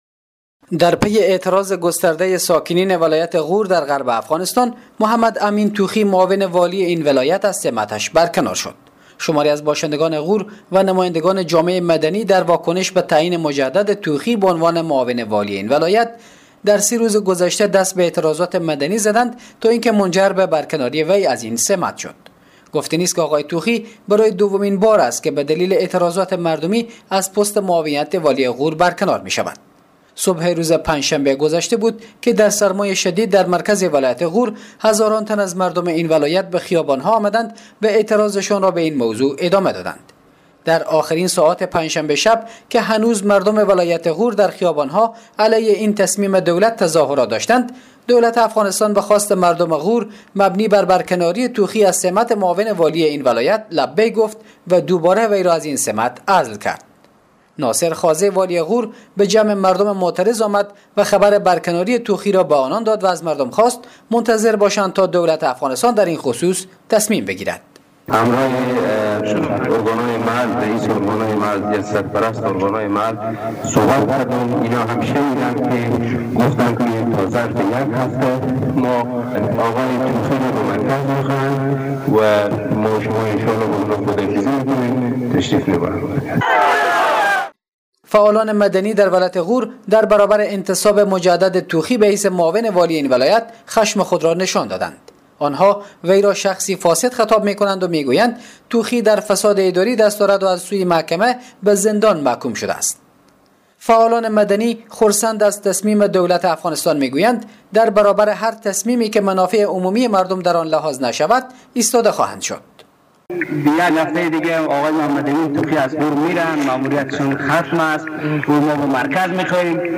جزئیات بیشتر این خبر در گزارش